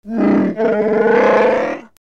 It is not an easy language for humans and other humanoids to learn, as most of the sounds emanate as growls and howls from the back of the throat.
Wookiee 3
SHYRIIWOOK LANGUAGE SAMPLE SOUNDS